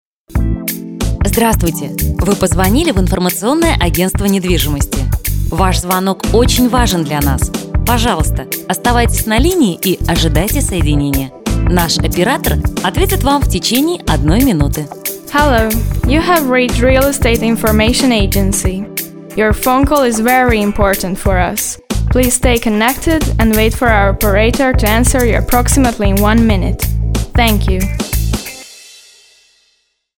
Приветствие на АТС, русс+англ, 2 диктора.